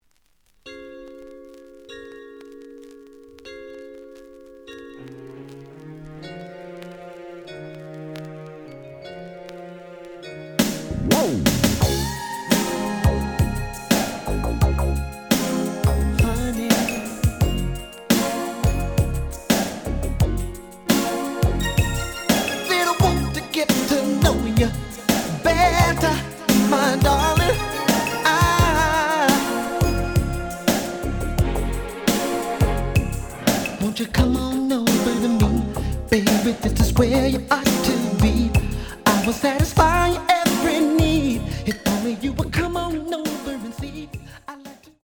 The audio sample is recorded from the actual item.
●Genre: Soul, 80's / 90's Soul
Slight noise on beginning of A side, but almost good.